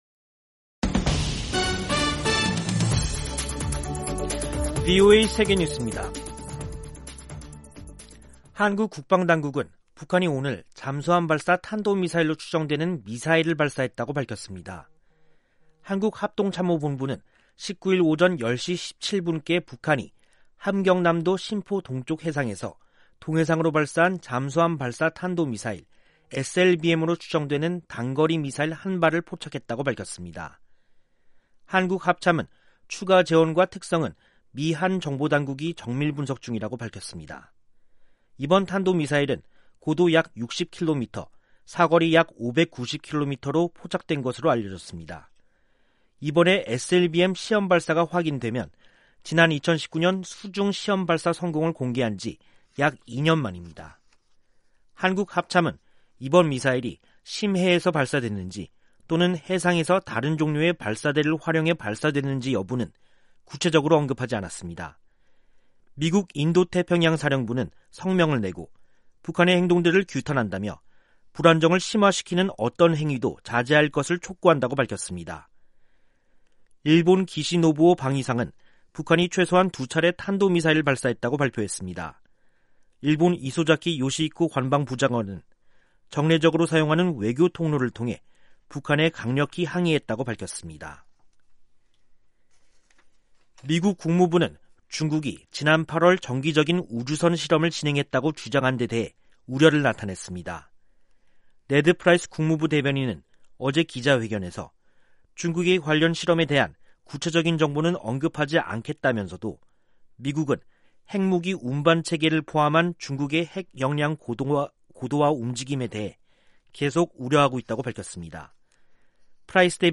세계 뉴스와 함께 미국의 모든 것을 소개하는 '생방송 여기는 워싱턴입니다', 2021년 10월 19일 저녁 방송입니다. '지구촌 오늘'에서는 로이드 오스틴 미 국방장관의 우크라이나 방문 이야기, '아메리카 나우'에서는 콜린 파월 전 국무장관이 코로나 합병증으로 타계한 소식 전해드립니다.